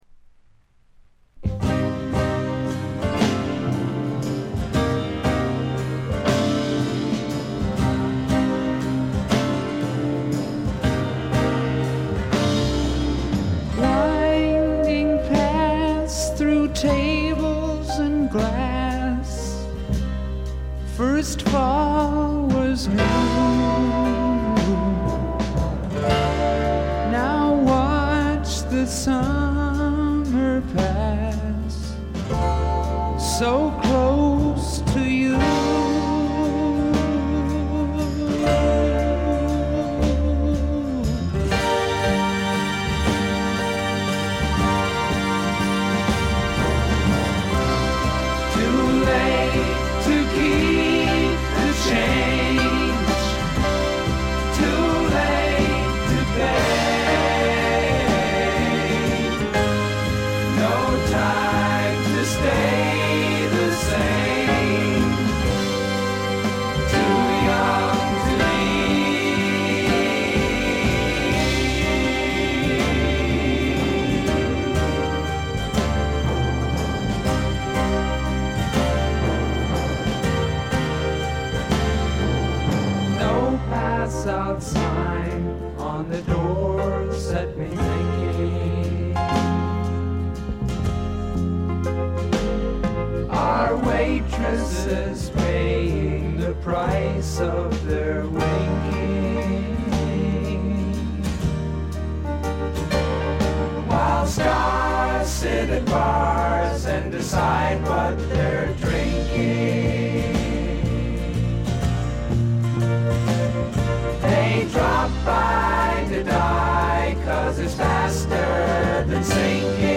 部分試聴ですが静音部での微細なバックグラウンドノイズ程度。
試聴曲は現品からの取り込み音源です。